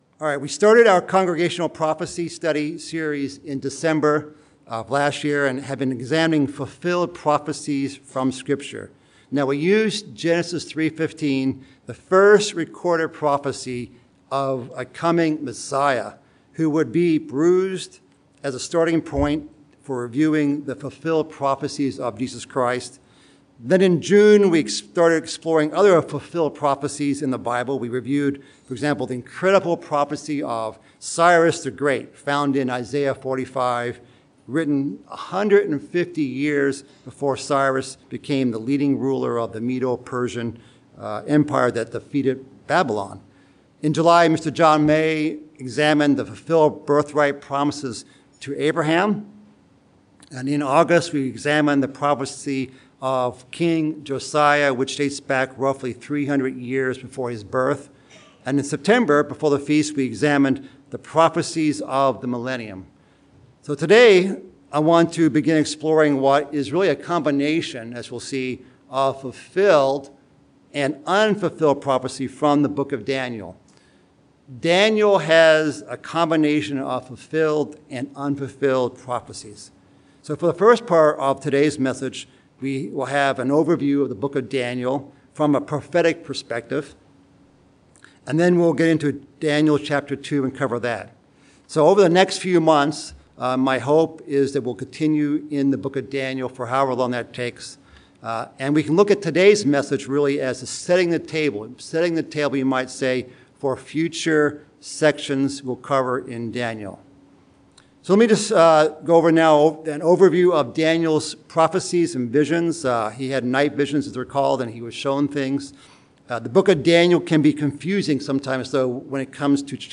Sermons
Given in Chicago, IL Northwest Indiana Beloit, WI